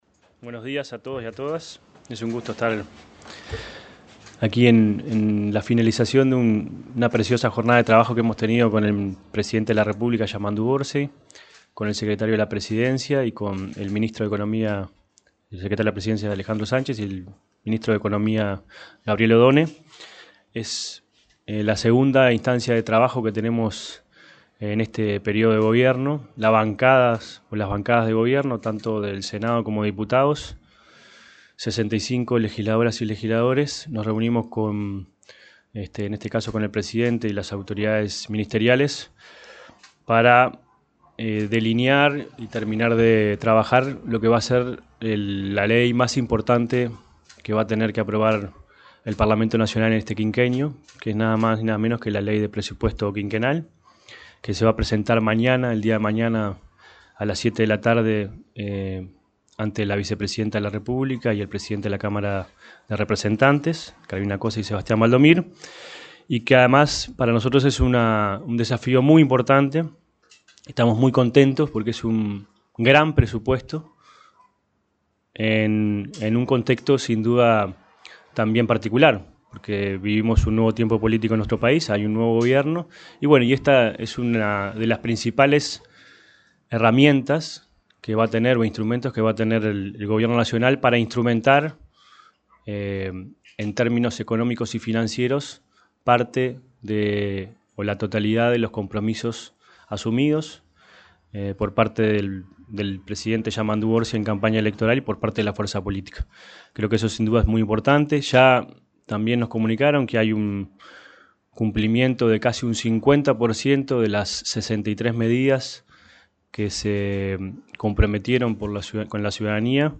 Conferencia de prensa de legisladores Daniel Caggiani y Ana Olivera
Conferencia de prensa de legisladores Daniel Caggiani y Ana Olivera 30/08/2025 Compartir Facebook X Copiar enlace WhatsApp LinkedIn El senador Daniel Caggiani y la diputada Ana Olivera informaron, en conferencia de prensa, acerca de la reunión de coordinación que mantuvieron los legisladores del Frente Amplio con el presidente de la República, Yamandú Orsi, sobre el proyecto de Ley de Presupuesto Nacional.